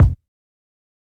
BattleCatKick2.wav